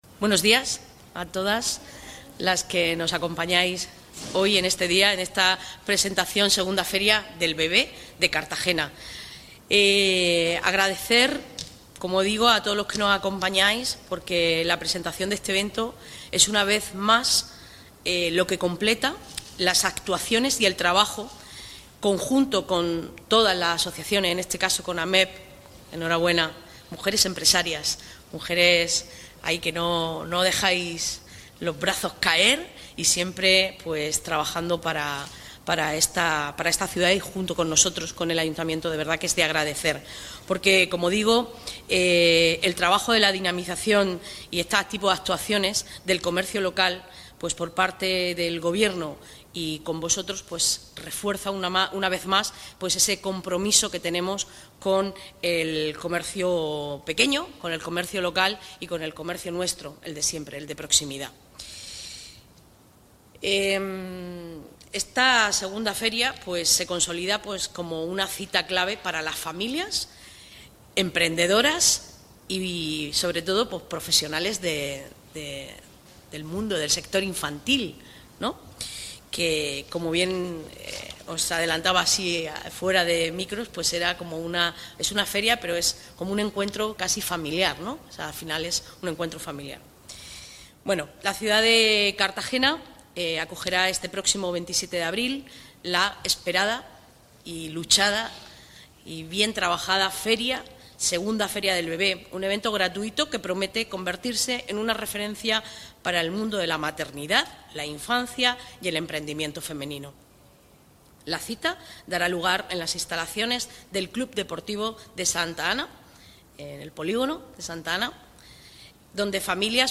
Enlace a Presentación de la II Feria del Bebé